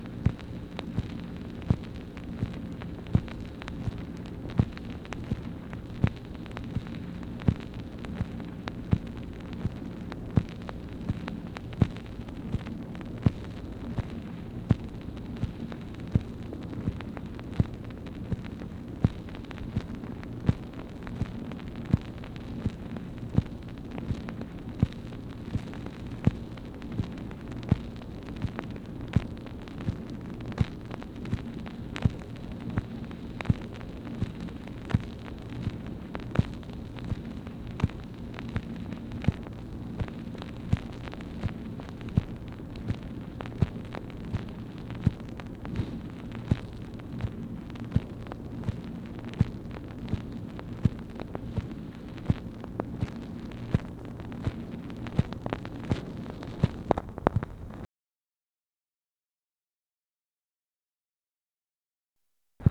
MACHINE NOISE, May 4, 1964
Secret White House Tapes